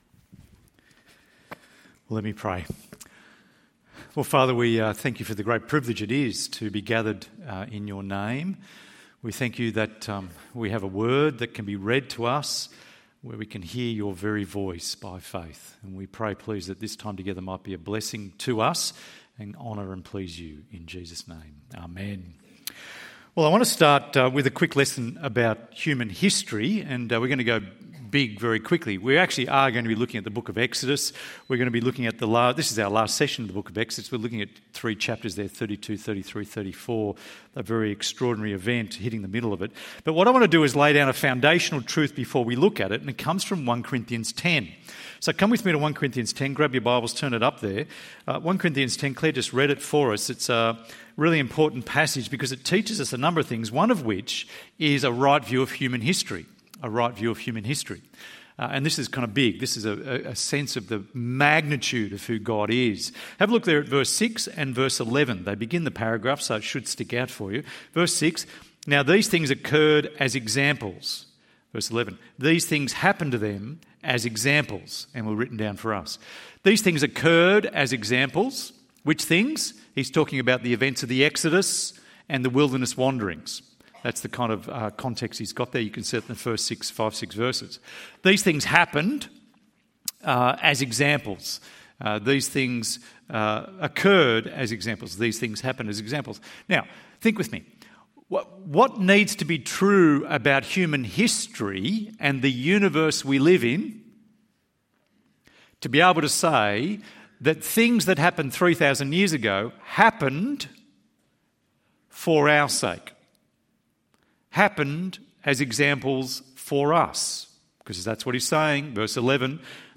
God hates idolatry (Golden calf & covenant renewal) ~ EV Church Sermons Podcast